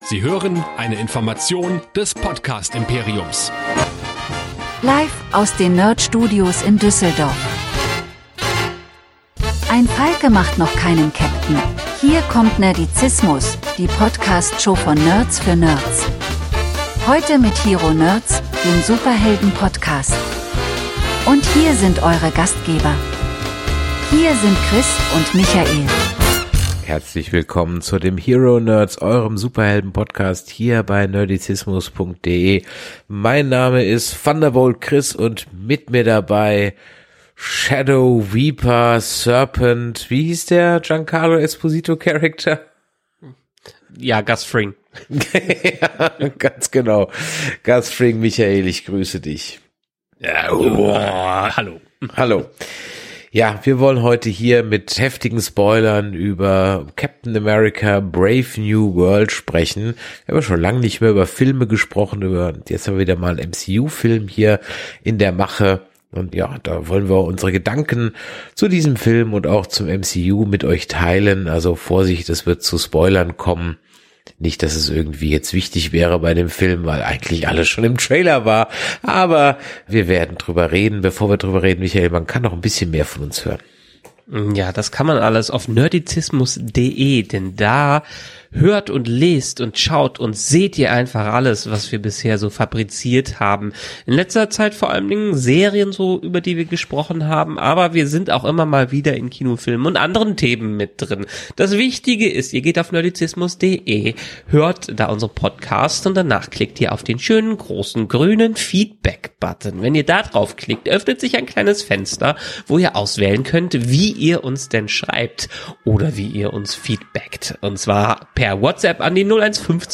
Und wieso fühlt sich „Brave New World“ eher wie ein aufgeblasener TV-Film an, anstatt das Kino zu rocken? Das klären die Hero Nerds mit jeder Menge Energie, Humor und einer Prise nerdiger Frustration über ungenutzte Potenziale.